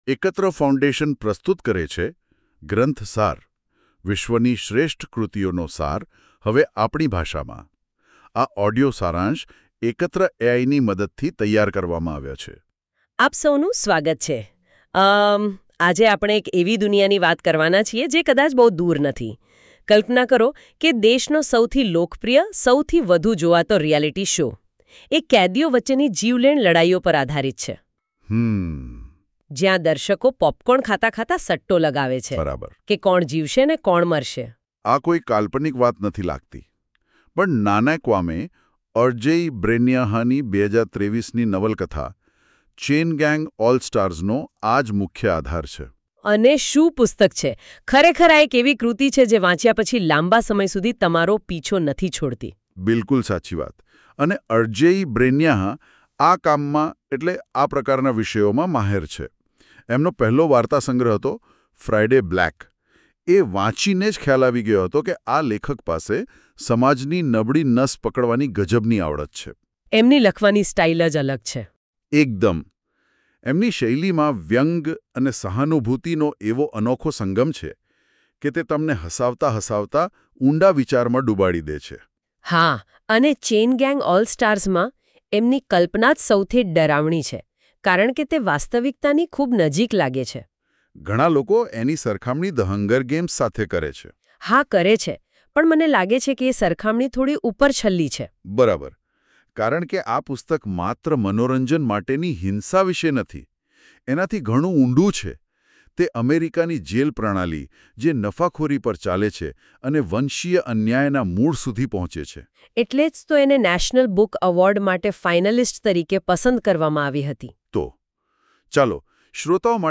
Ekatra audio summary – Gujarati